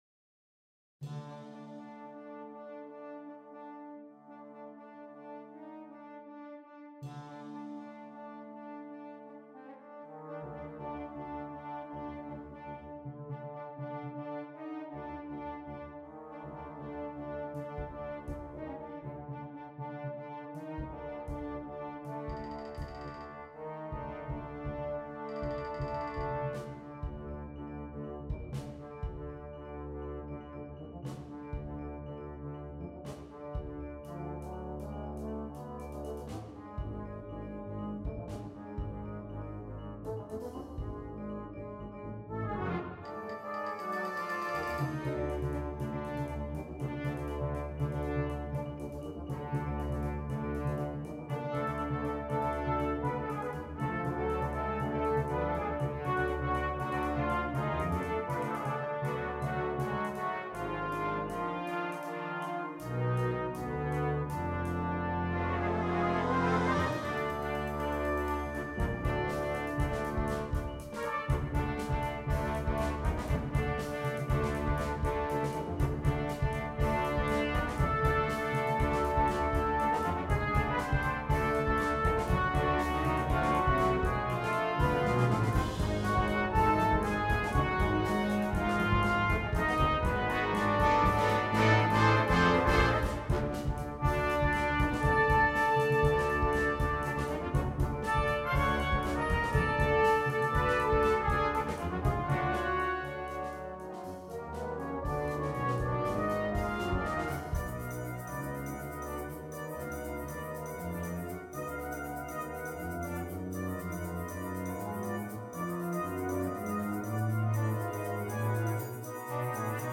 A Fantasy for Brass Band